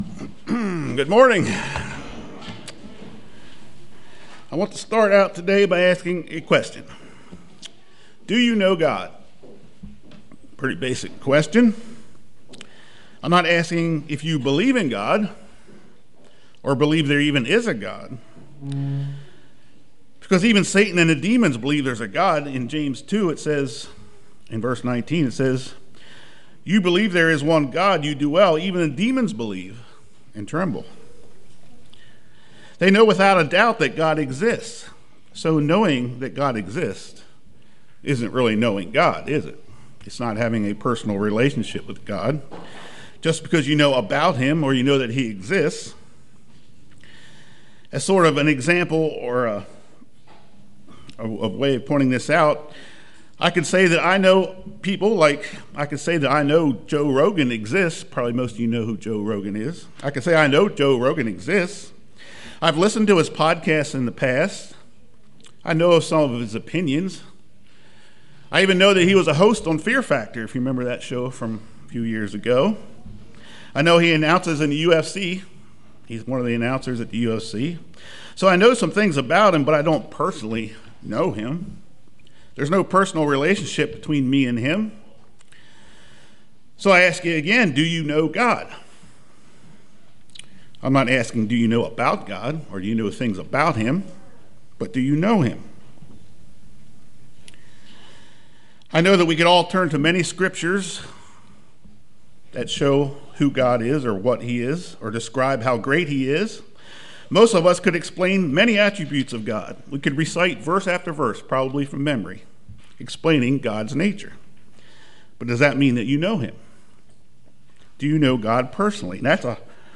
In his sermon